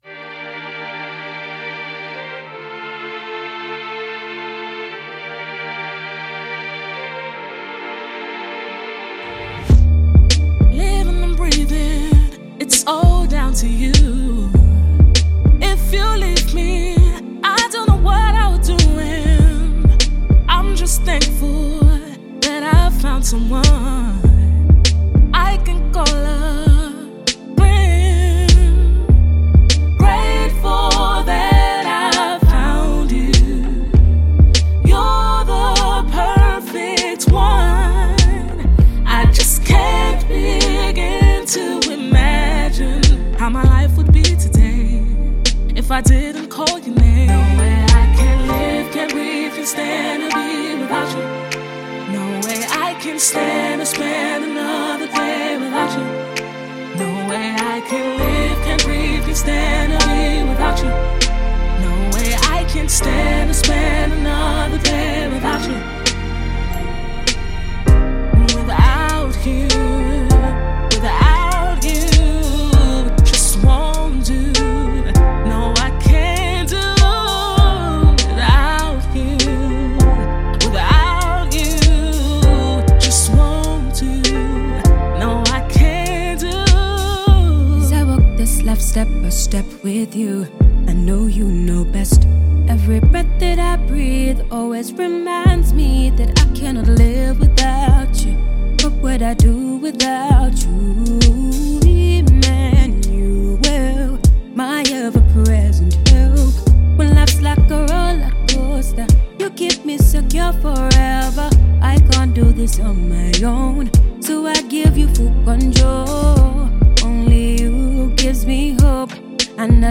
UK gospel soulstress
a soulful, expressive, and transparent summer single
skilfully blended voices
Christian R&B